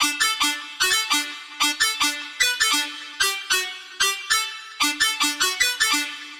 150BPM Lead 05 Dmaj.wav